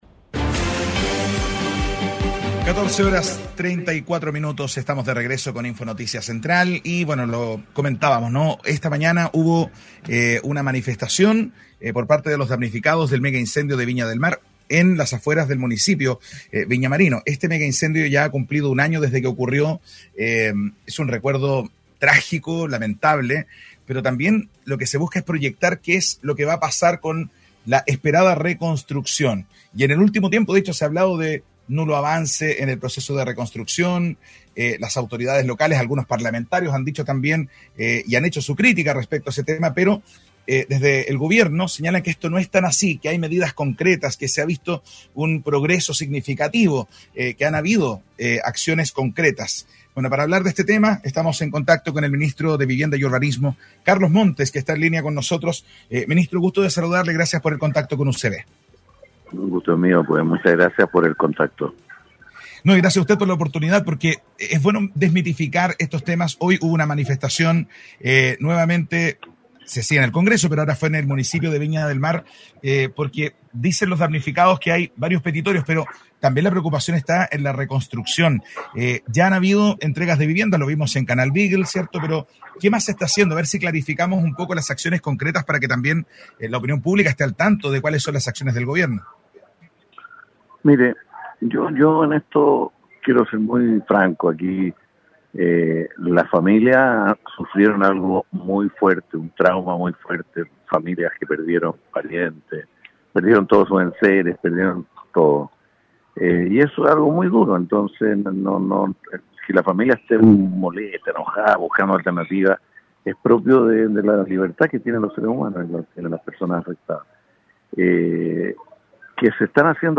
Ministro de Vivienda en entrevista con UCV Radio: